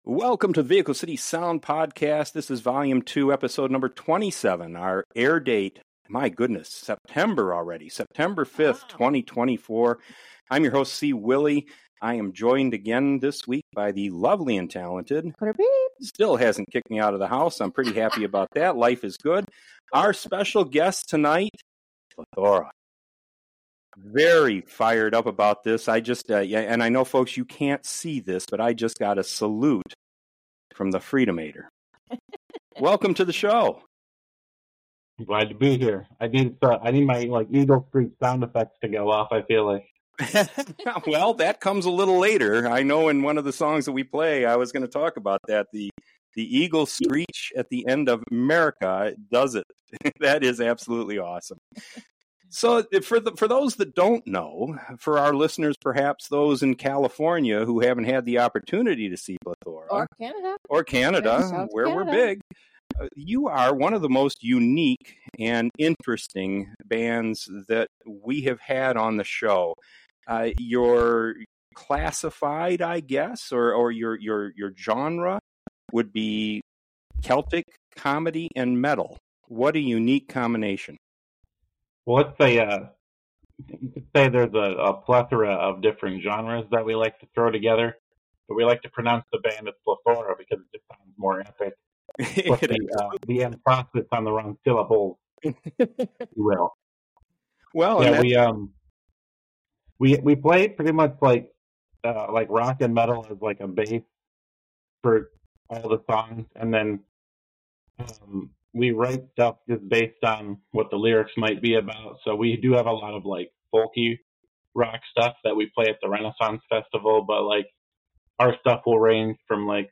You'll rock out with this one!